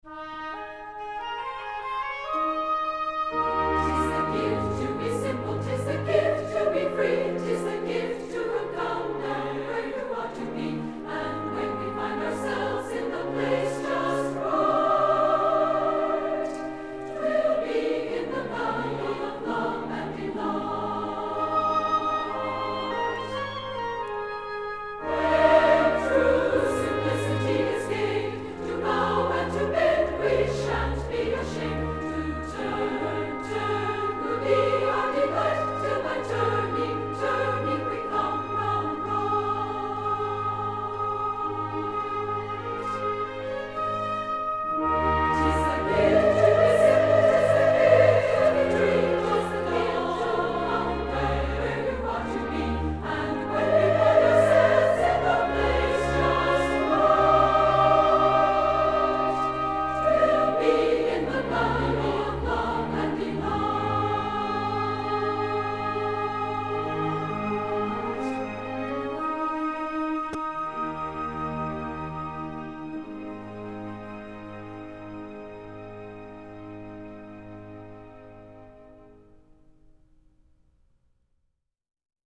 Música vocal